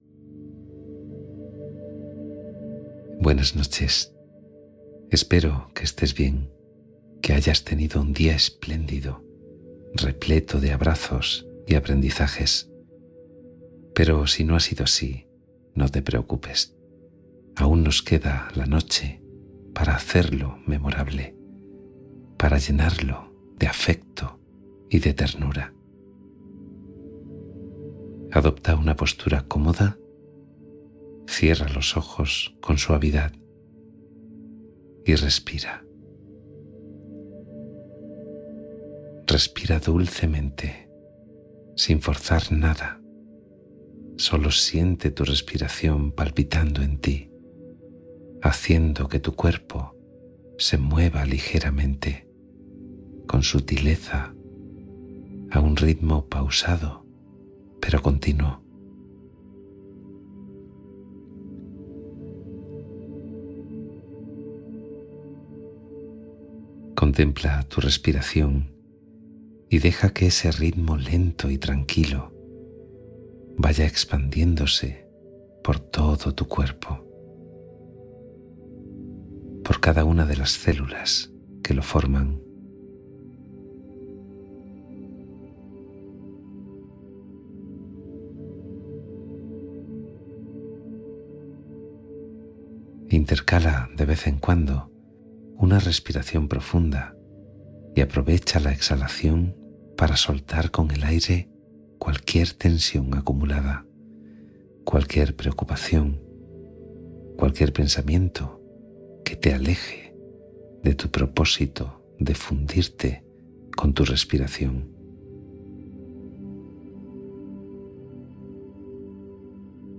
Meditación Relajante con Palabras de Autoestima y Contención